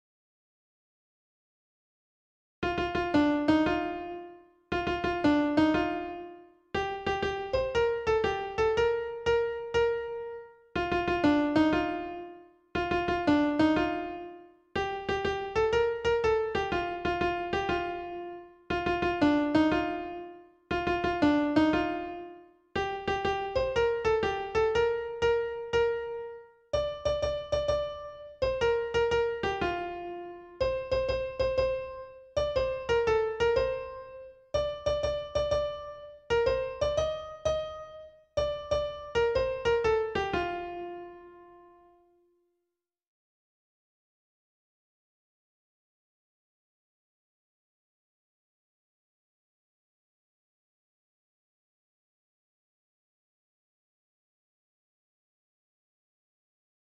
Perfect piano